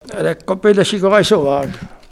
Olonne-sur-Mer
locutions vernaculaires